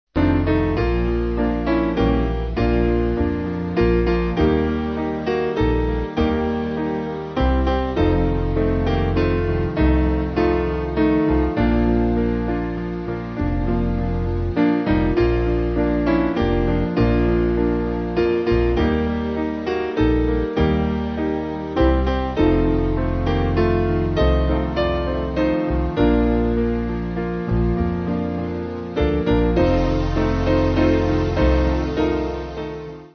Small Band
No Percussion